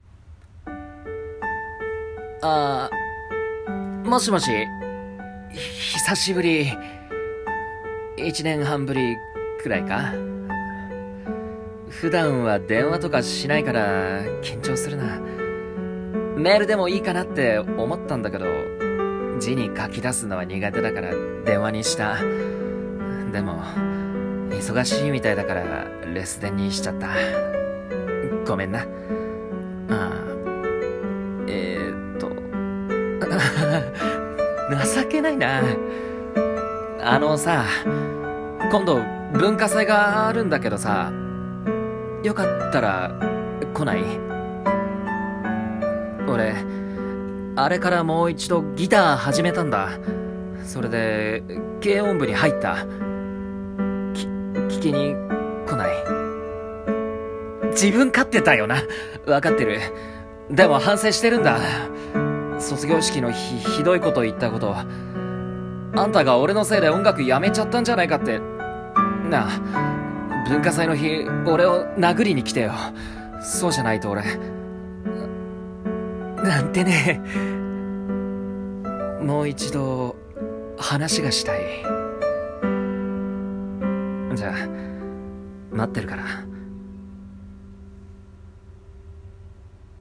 声劇】 話がしたいんだ。